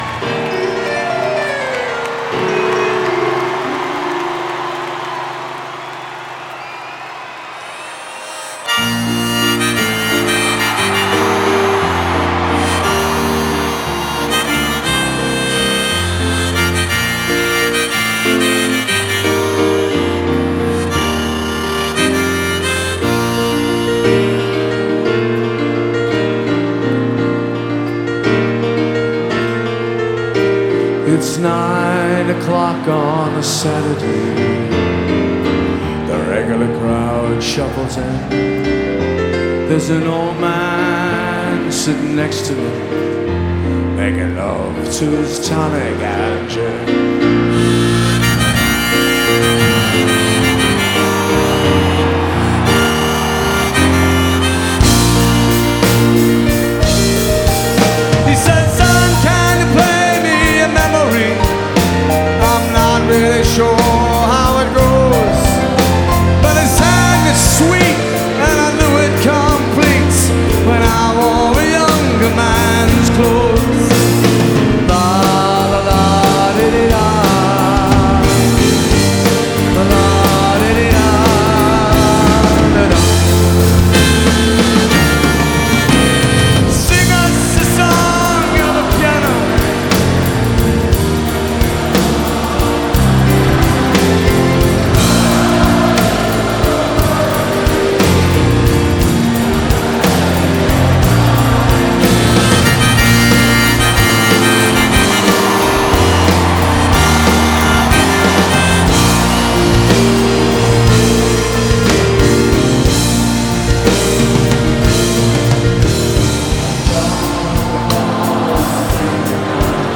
US • Genre: Rock, Pop